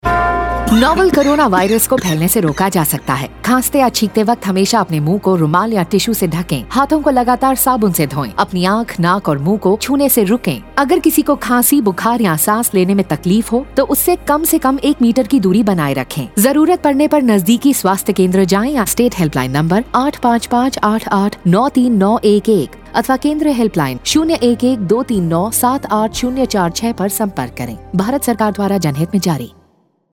Radio PSA
5141_Cough Radio_Hindi_Haryana.mp3